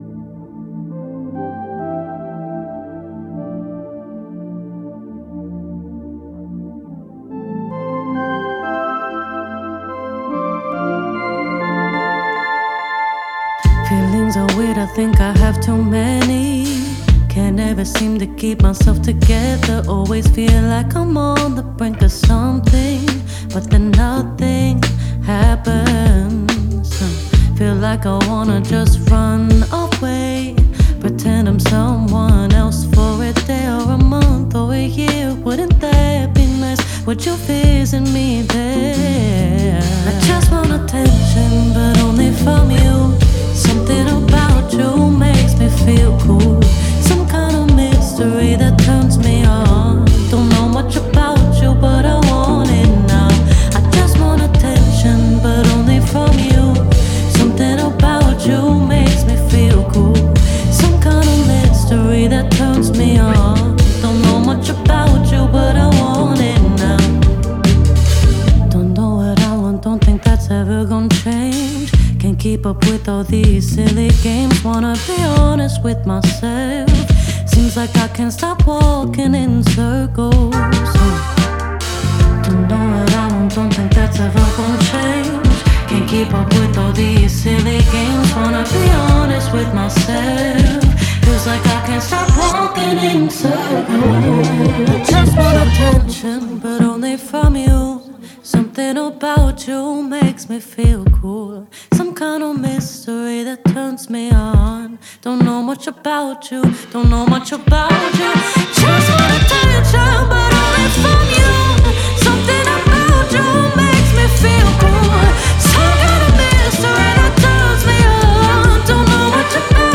Recorded at various studios in Philadelphia PA.